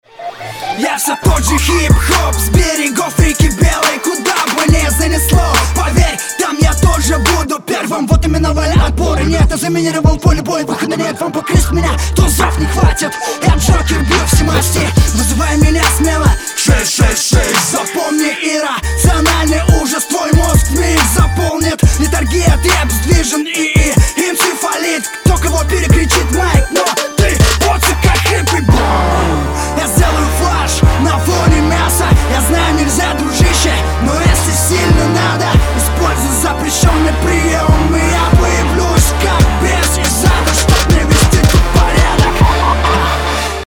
Паузы местами плохо звучат.
Хотя зачитал неплохо.